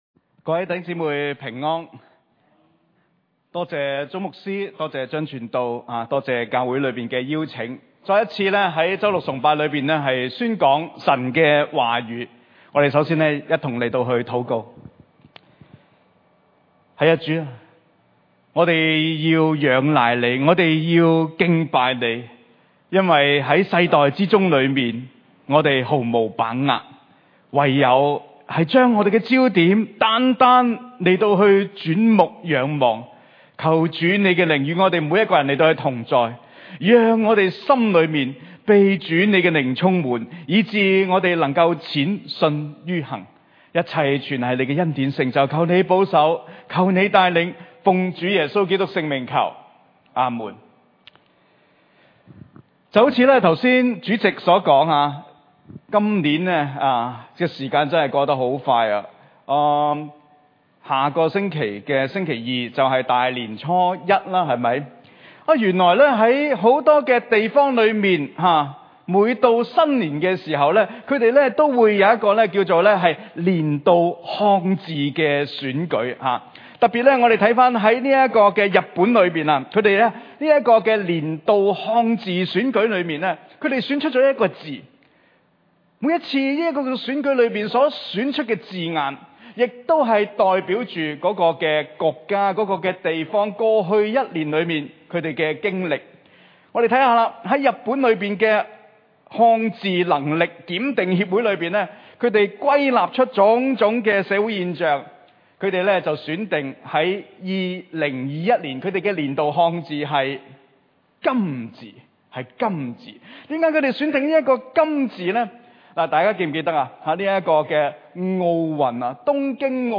2022年1月29日崇拜 (週六晚堂) – 鑽石山浸信會
講道大綱及錄音